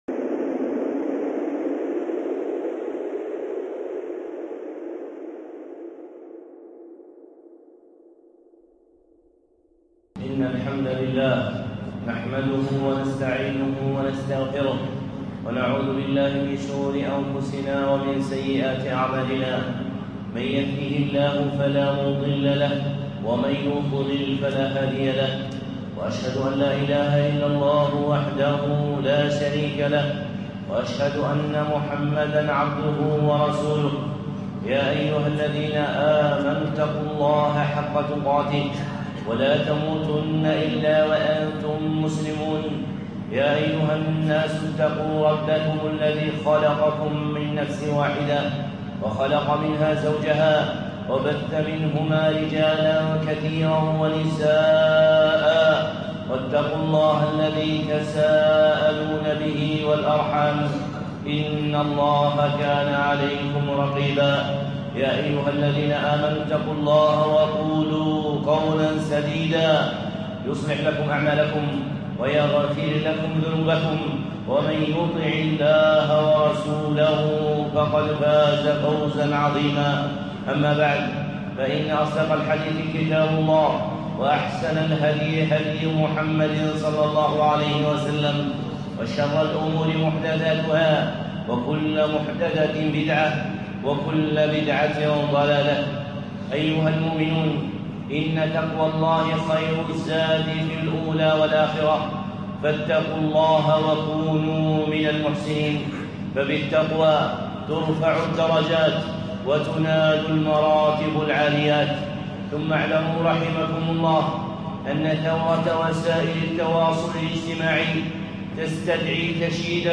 خطبة (القول المتبَع)